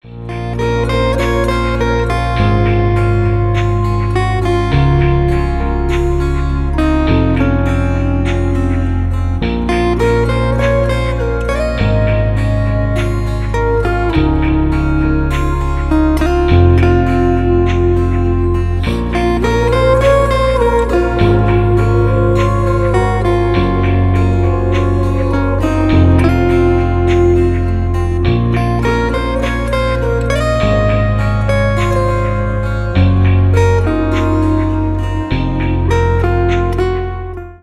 • Качество: 320 kbps, Stereo
Поп Музыка
спокойные
без слов